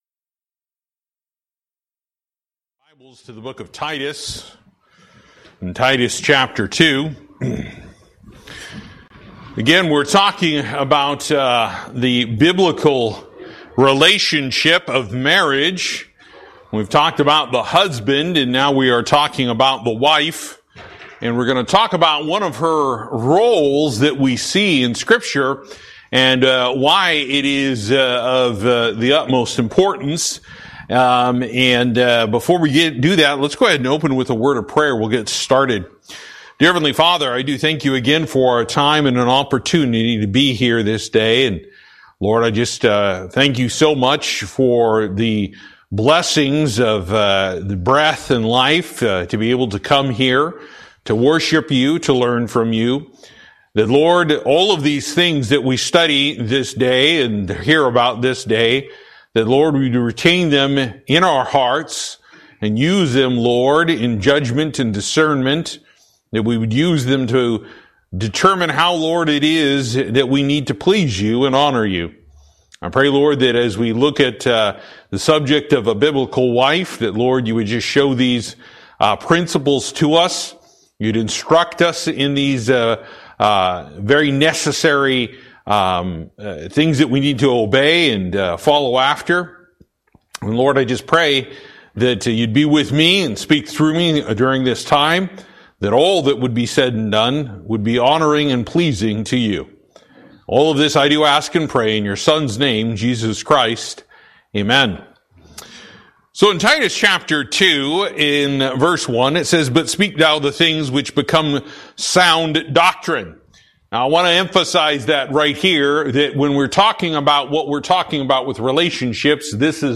Service: Sunday School